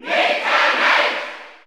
Category: Crowd cheers (SSBU) You cannot overwrite this file.
Meta_Knight_Cheer_German_SSBU.ogg